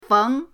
feng2.mp3